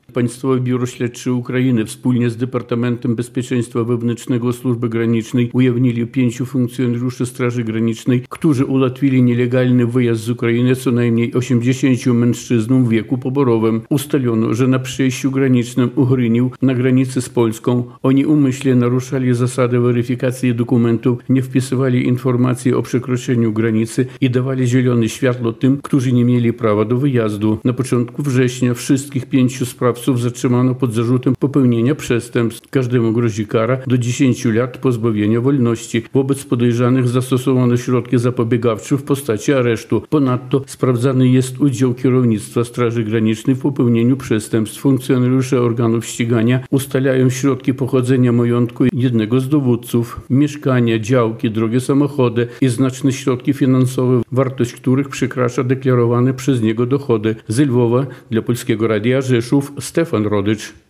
Ze Lwowa dla Polskiego Radia Rzeszów